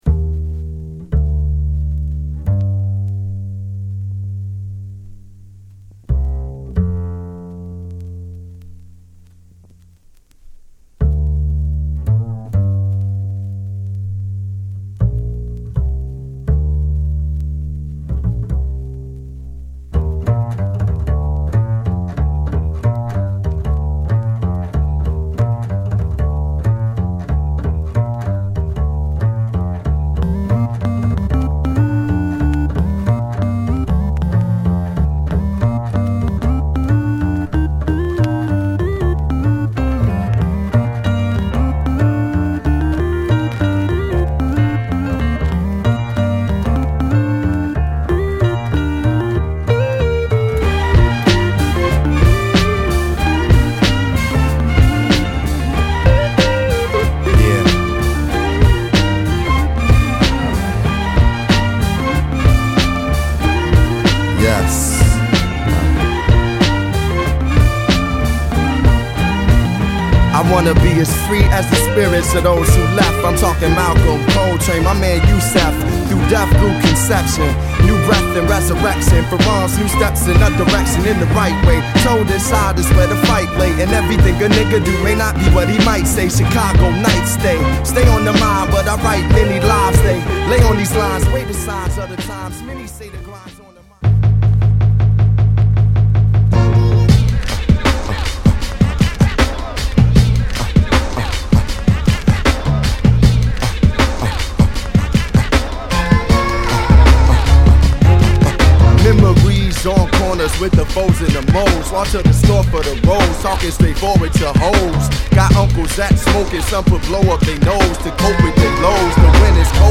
ソウルフルかつジャズテイストを散りばめた素晴らしい内容！